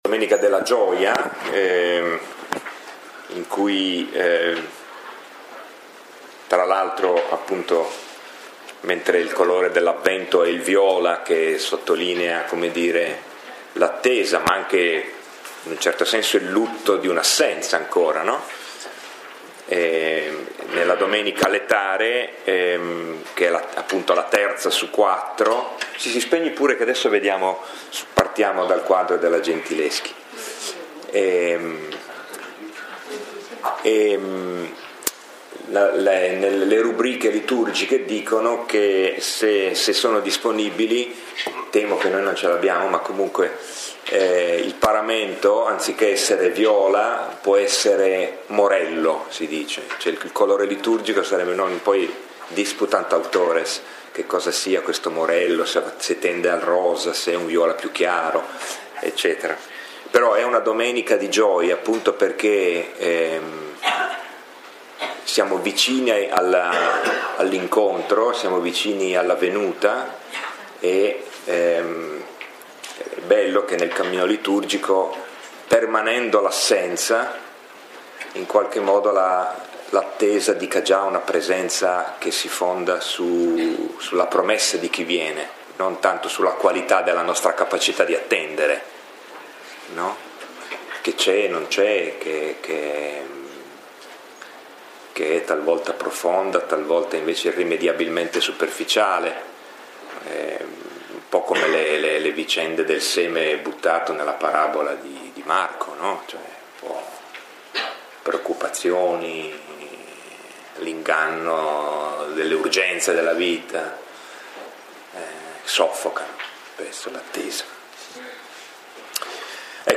Lectio 3 – 17 dicembre 2017